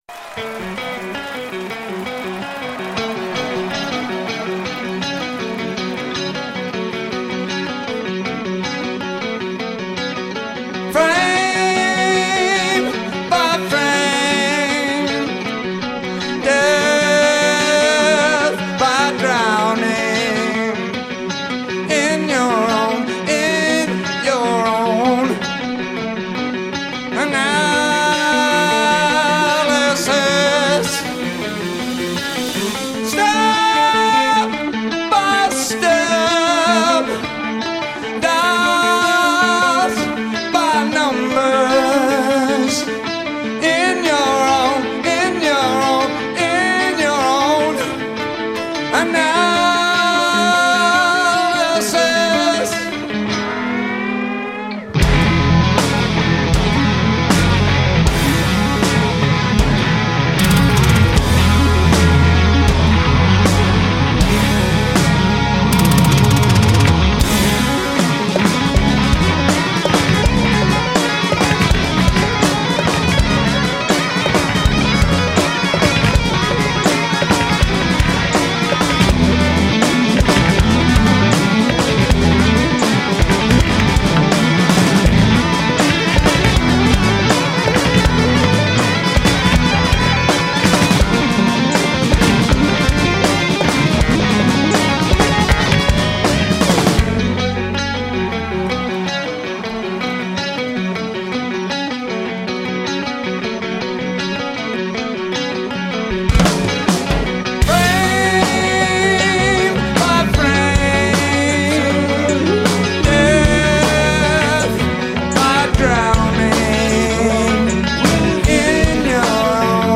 Un rock minimalista, storicamente informato